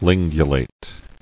[lin·gu·late]